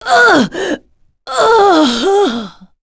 Pavel-Vox_Dead_b.wav